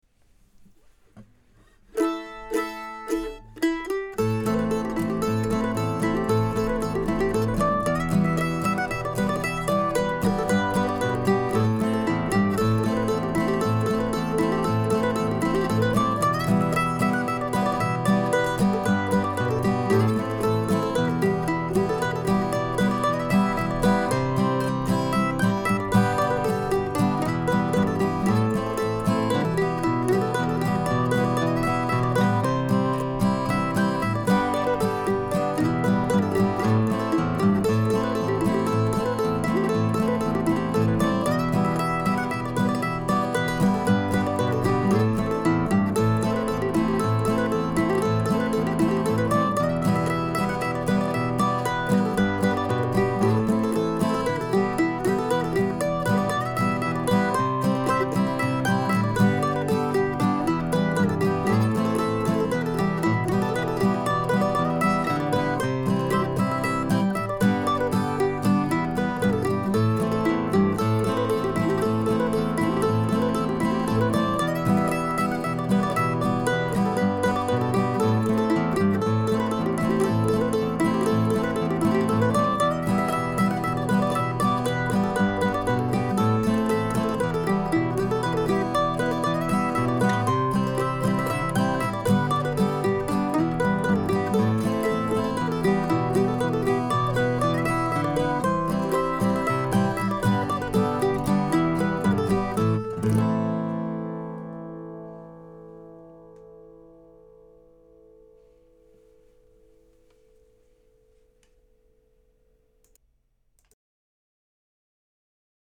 Snow Crow Shadow ( mp3 ) ( pdf ) Believe it or not, I try not to write every tune in either D major or A minor/modal.